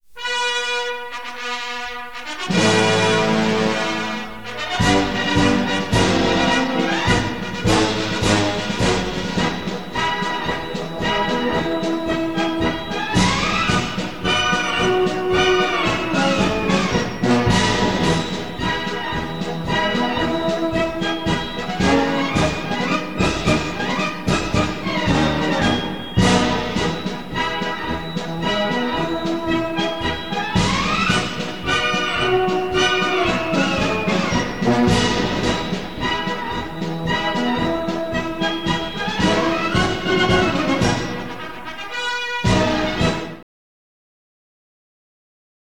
Приложение 4, музыка (вынос флагов)